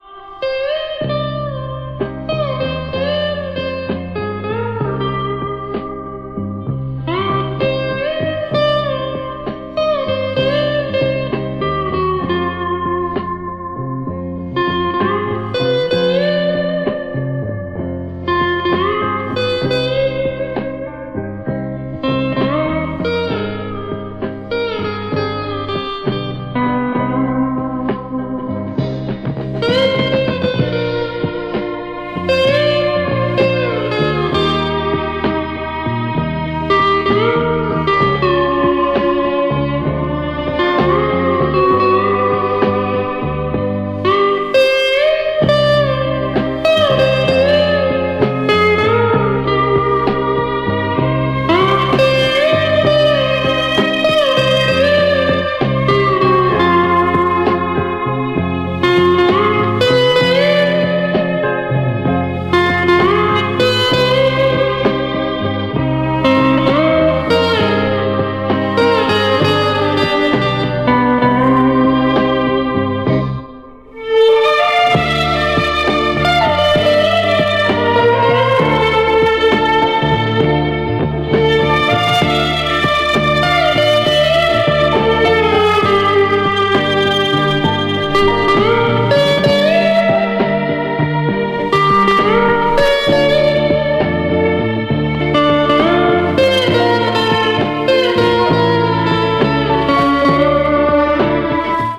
• Качество: 128, Stereo
саундтреки
спокойные
без слов
оркестр